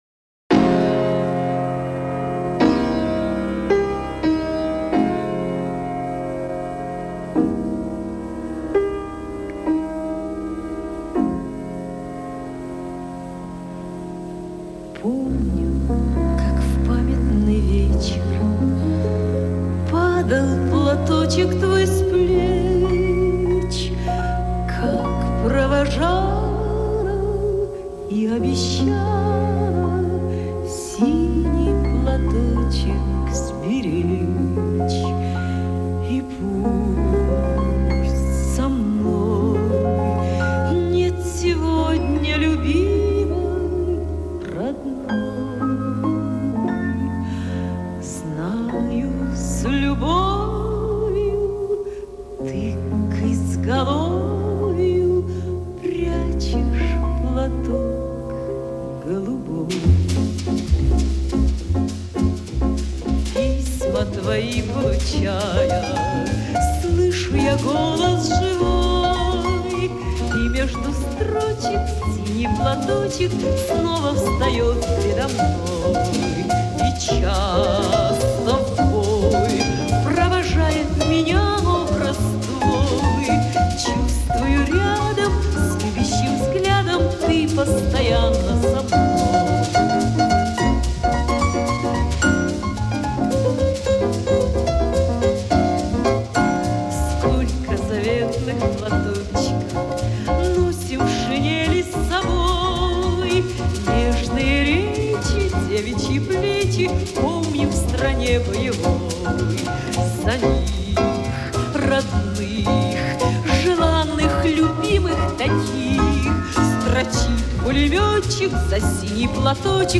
Источник в ленинградской передаче 1974 года